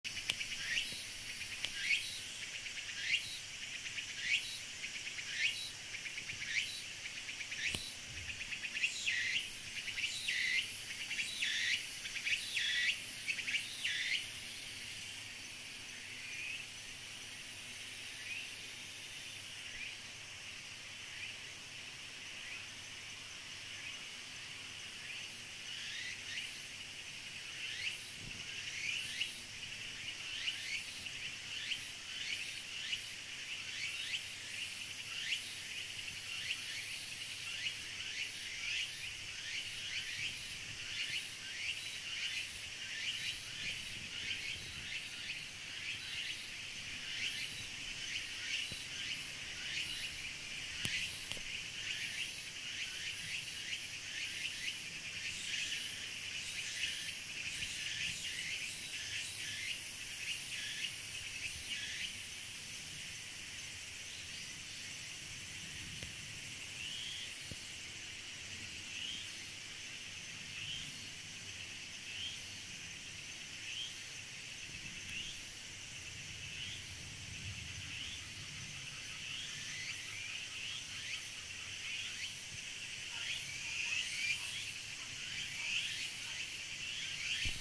つくつくほうしの鳴き声 / つくつくぼうし(蝉)